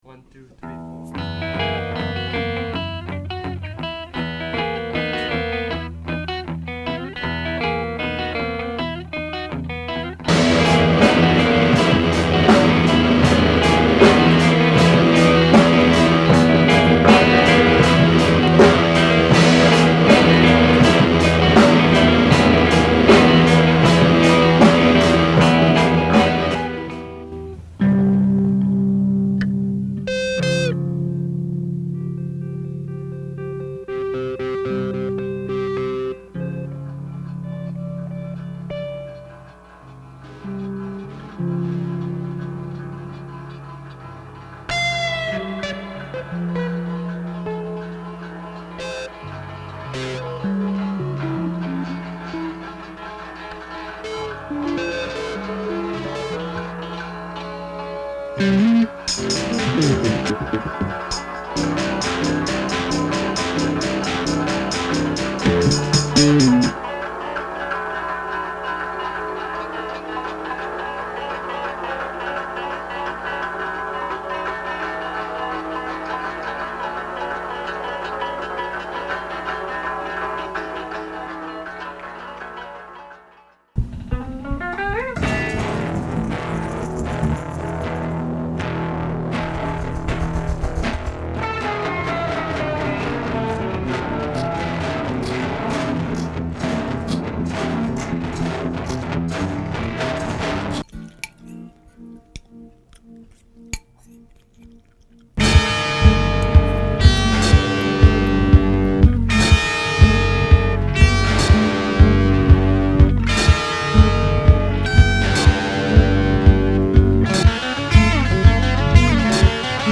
Not Well Hidden that has little bits and pieces of recordings from probably 2004 to (when it was edited together, I believe) 2020.